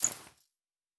Coin and Purse 02.wav